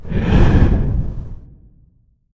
exhale.ogg